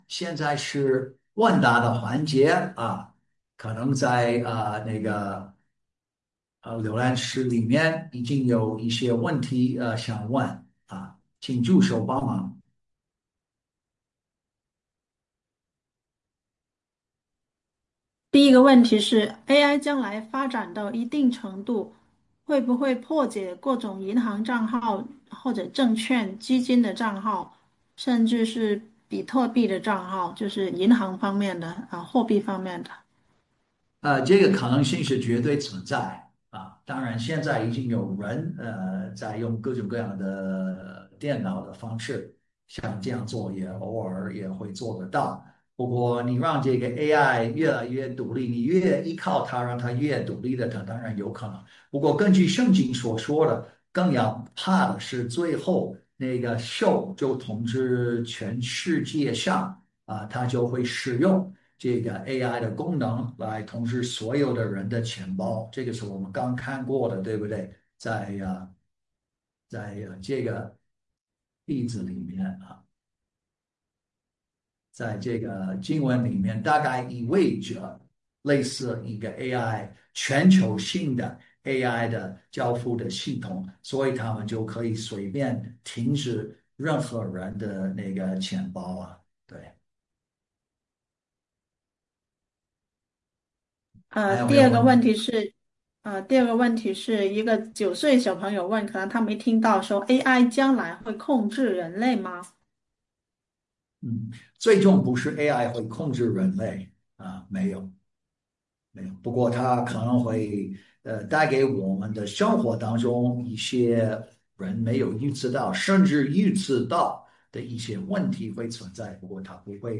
《A.I.人工智能与基督徒的生活和信仰》讲座直播回放